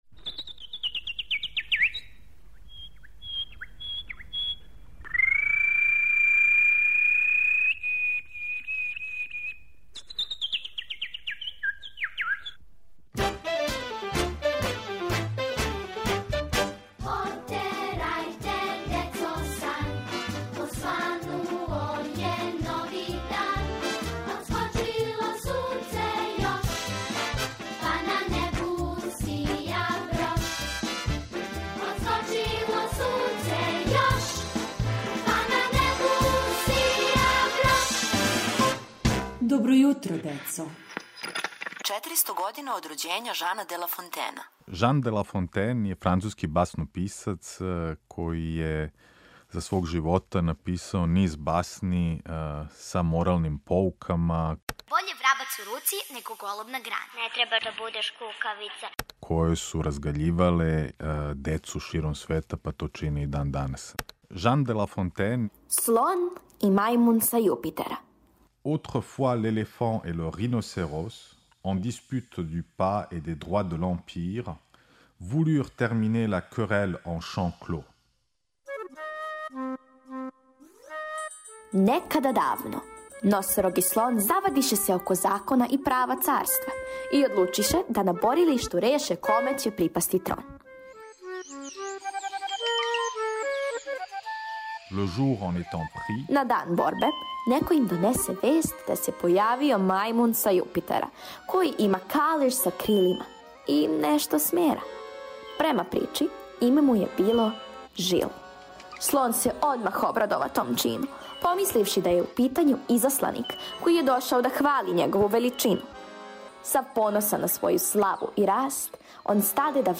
Серијал је обогаћен оригиналном музиком